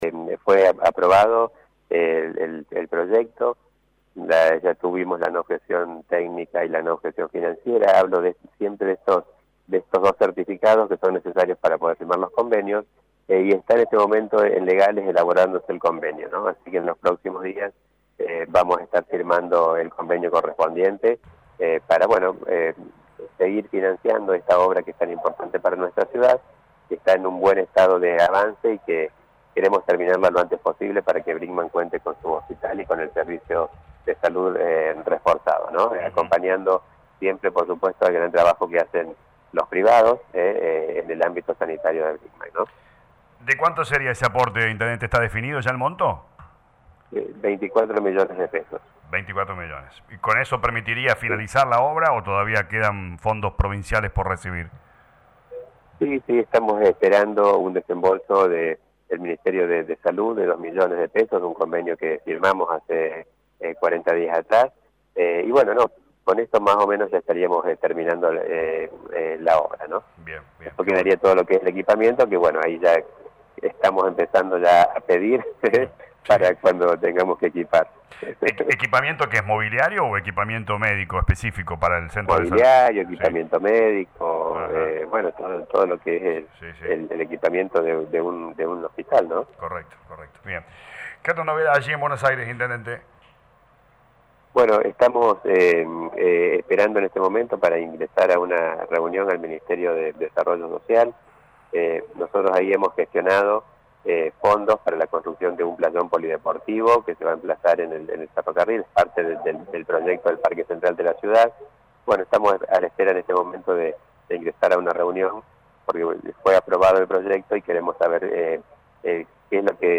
Lo confirmó el intendente municipal de Brinkmann Dr. Gustavo Tevez en dialogo con LA RADIO 102.9 durante su viaje por gestiones en Ciudad Autónoma de Buenos Aires.